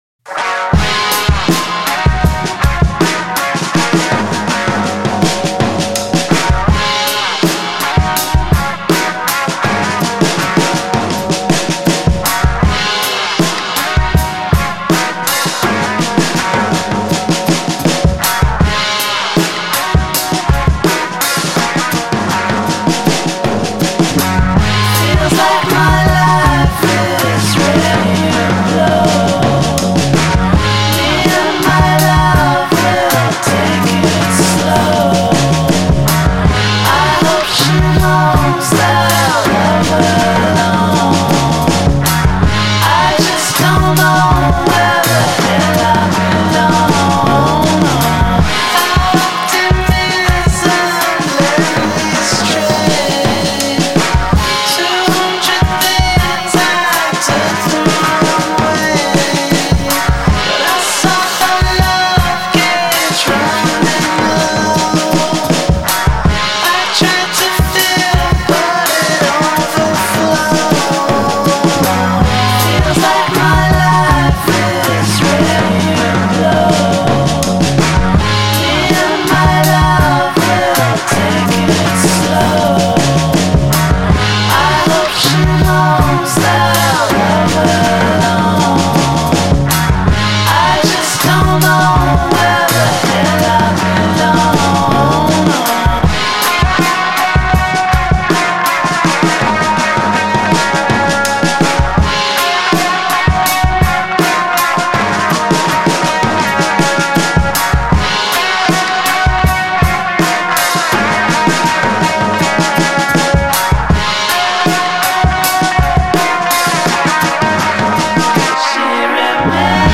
The band clearly shoots for a psychedelic late-60’s sound.